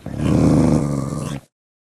growl1.ogg